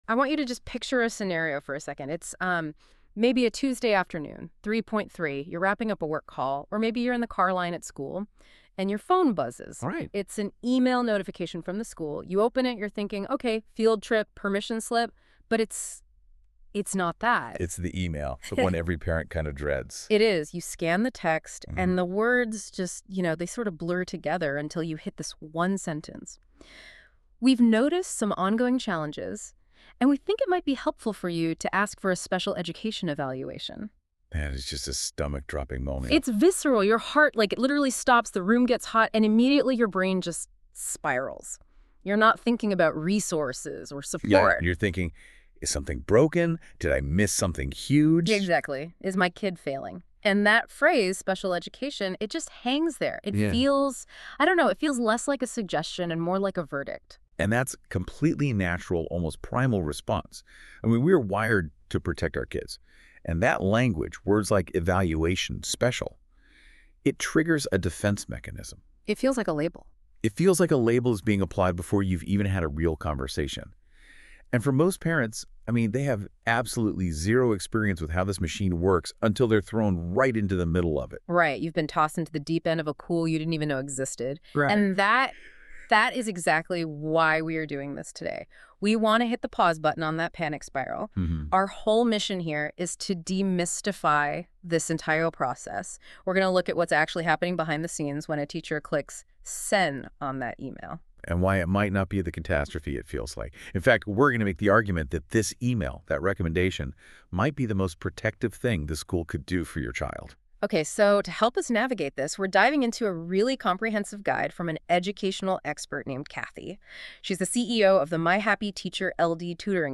This short audio overview shares the key ideas from this article in a podcast-style
( Quick note before you listen: This audio was created using AI and may include small errors in wording or delivery.